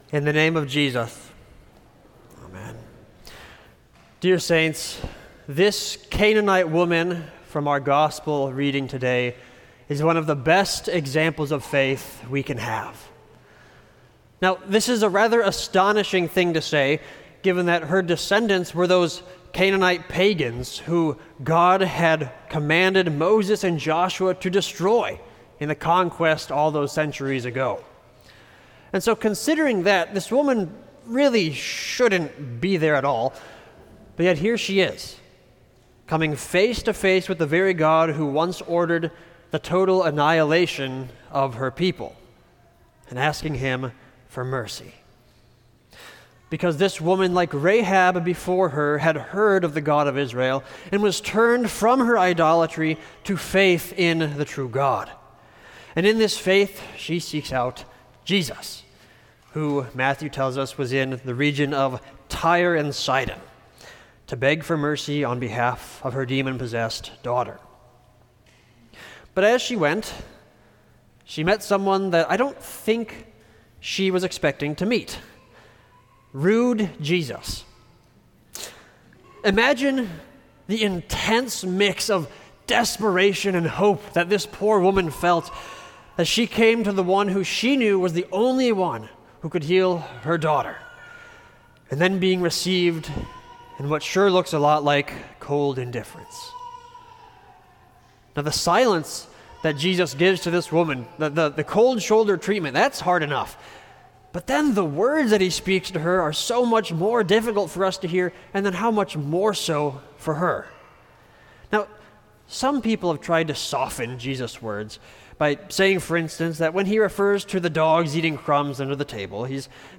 Sermon for Second Sunday in Lent